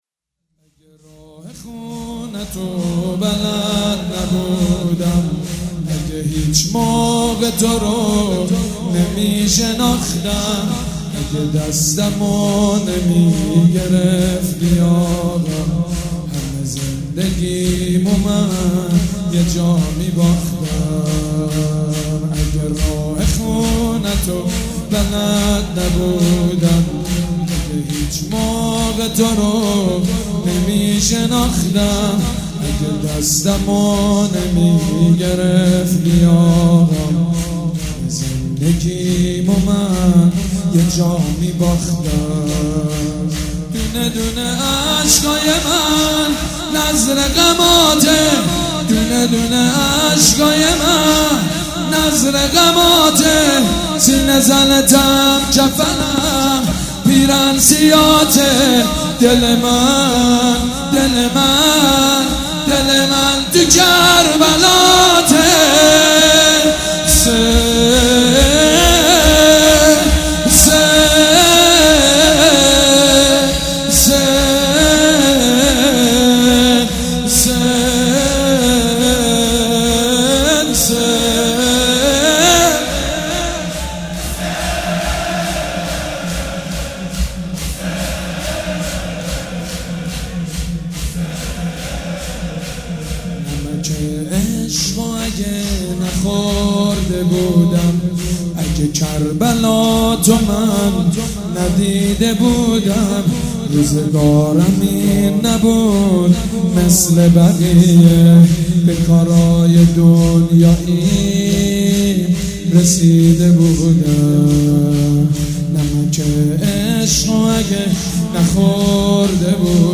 شب یازدهم محرم الحرام‌
شور
حاج سید مجید بنی فاطمه
مراسم عزاداری شب شام غریبان